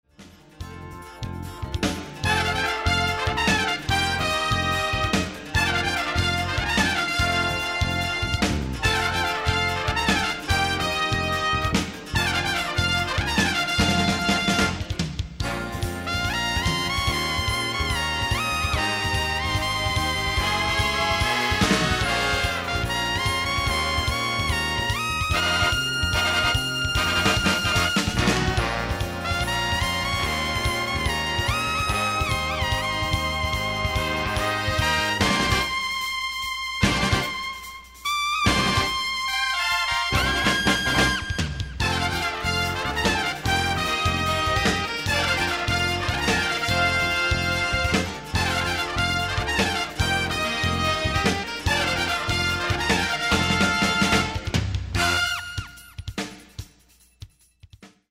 contemporary jazz instrumental